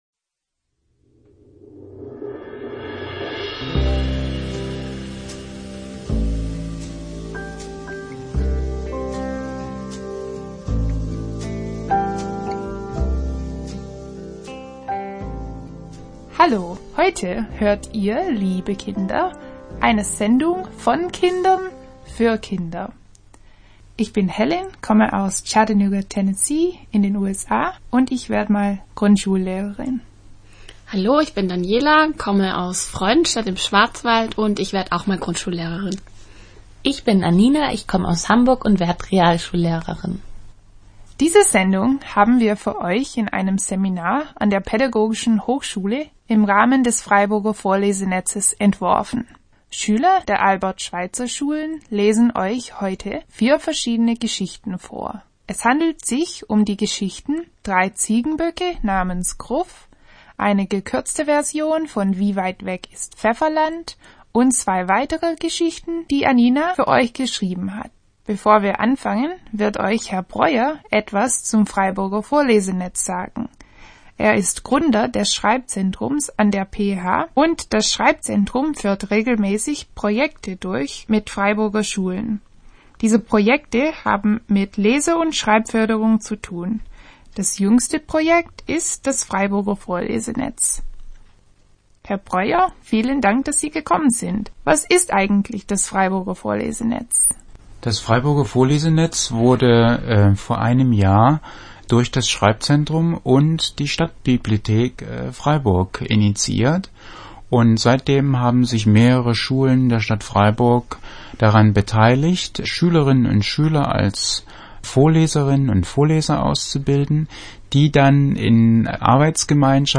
Radiosendung PF_1_1_ und PF_1_2_